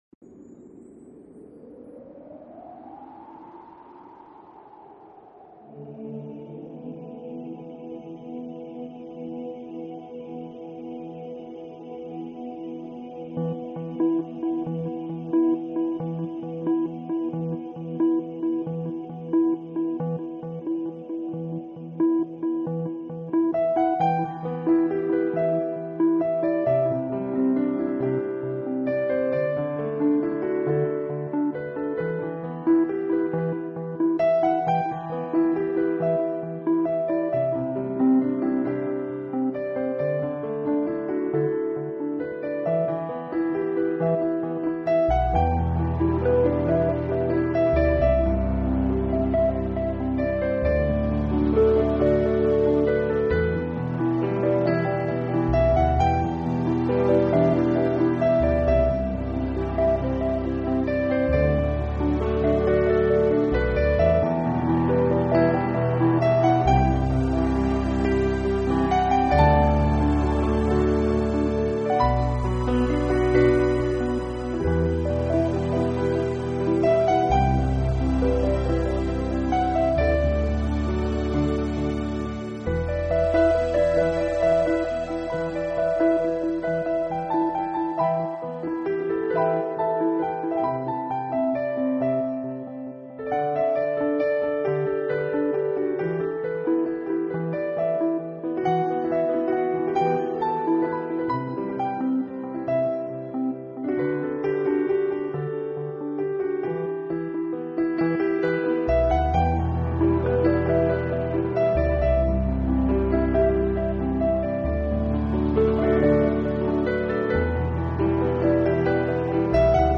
Genre..........: New Age
QUALiTY........: MP3 44,1kHz / Stereo
The melodies here
offer that calming experience.